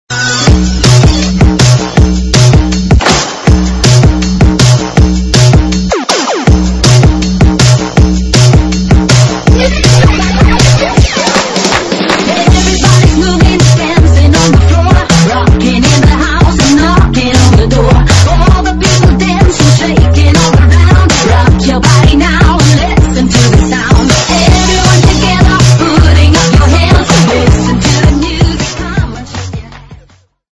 分类: MP3铃声